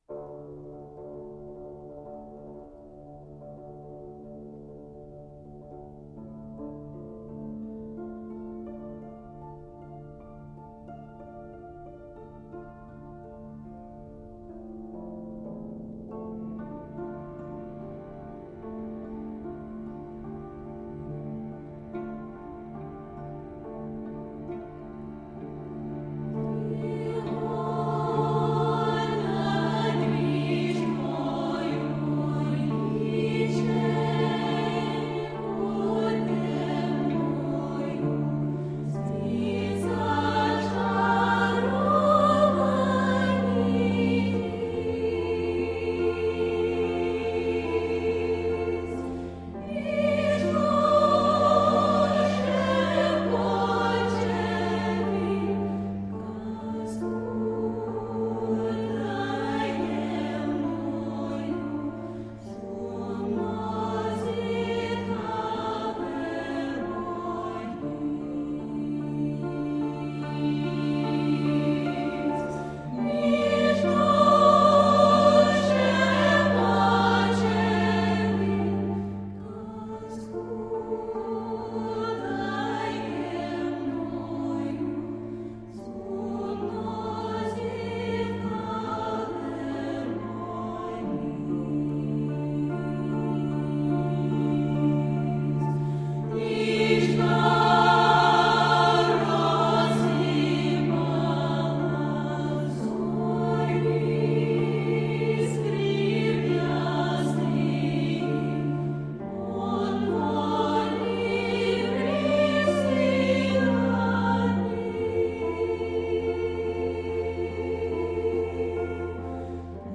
Eastern european women's vocal music.
Tagged as: World, Vocal, Eastern Voices